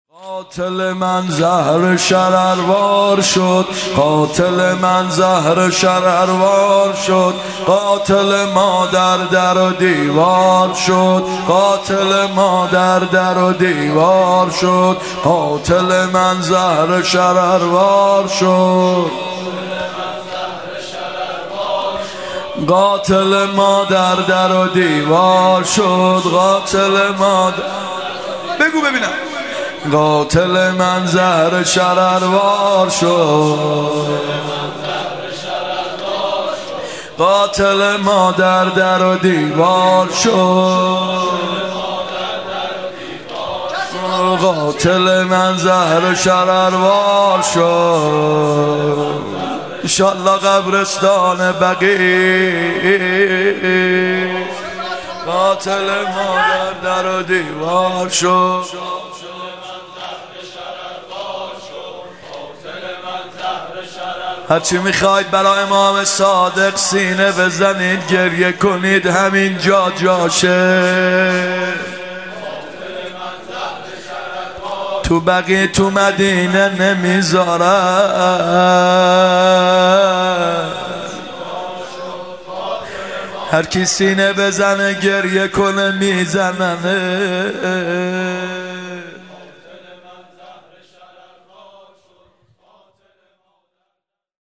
شهادت امام صادق ع (هیات یا مهدی عج)